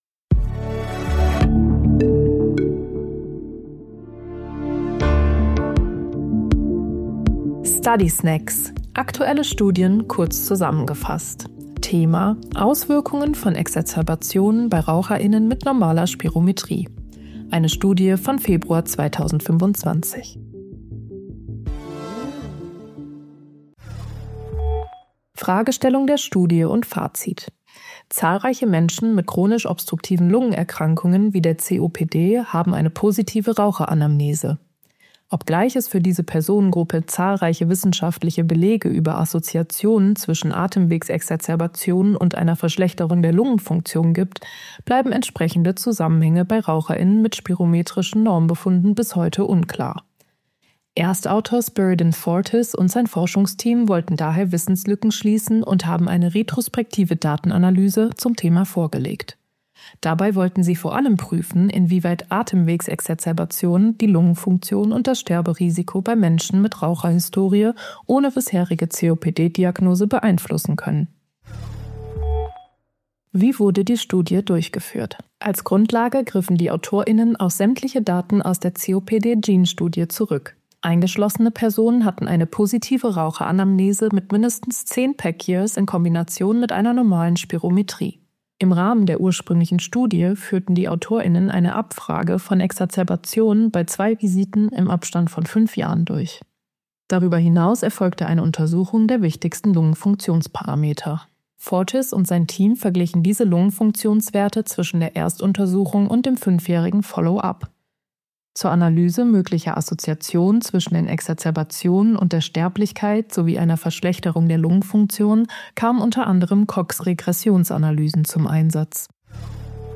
künstlicher Intelligenz (KI) oder maschineller